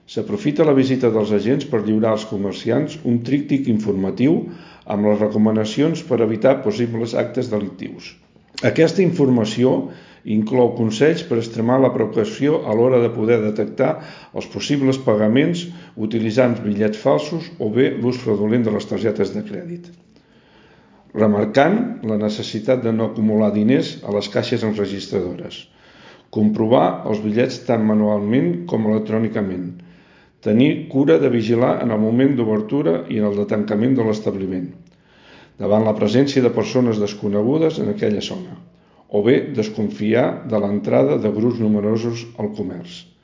Josep Santamaria, regidor de Seguretat i Mobilitat de Palamós, ha explicat a Ràdio Capital quines mesures s’han de tenir en compte de manera general.